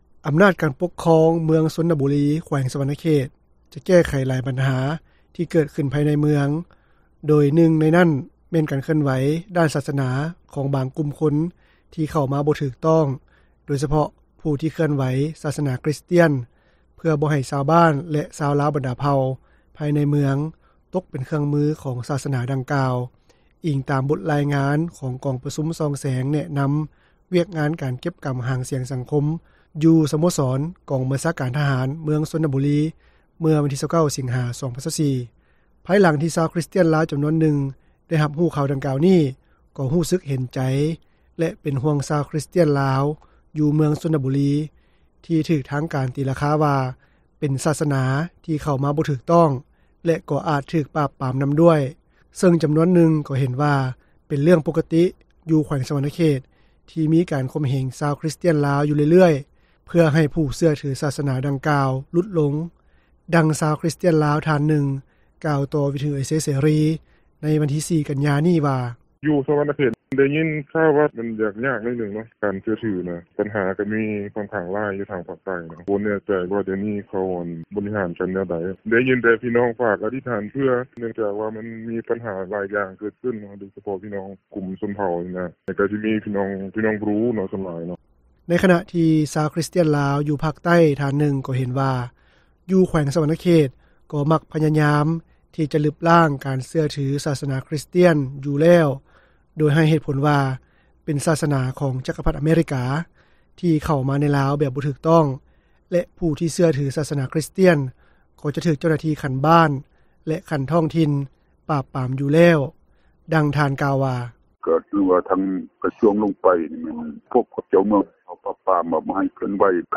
ເມືອງຊົນນະບູລີ ຈະແກ້ໄຂການເຄື່ອນໄຫວ ສາດສະໜາ ທີ່ບໍ່ຖືກຕ້ອງ — ຂ່າວລາວ ວິທຍຸເອເຊັຽເສຣີ ພາສາລາວ